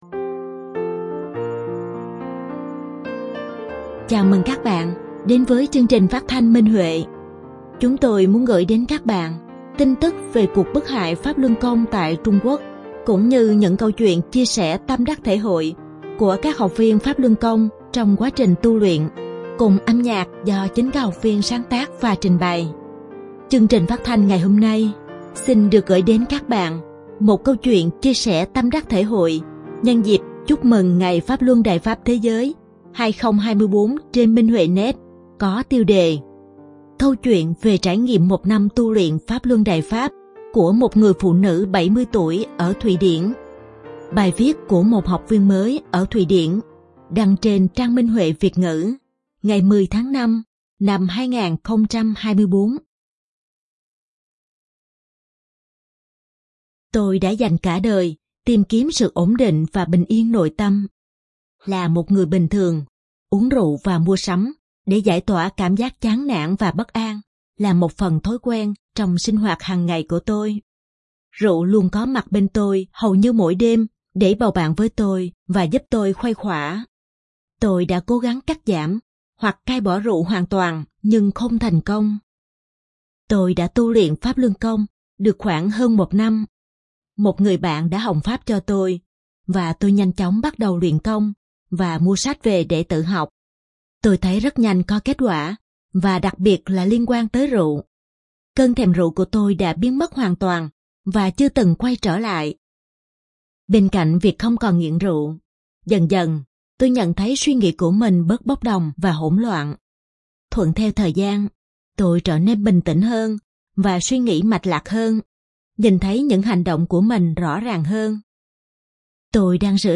Chương trình phát thanh số 21: Bài viết chia sẻ tâm đắc thể hội nhân dịp Chúc mừng Ngày Pháp Luân Đại Pháp Thế giới trên Minh Huệ Net có tiêu đề Câu chuyện về trải nghiệm một năm tu luyện Pháp Luân Đại Pháp của một người phụ nữ 70 tuổi ở Thụy Điển, bài viết của đệ tử Đại Pháp tại Thụy Điển.